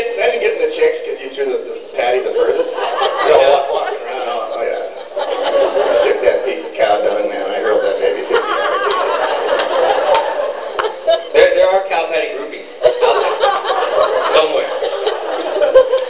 Gatecon 2003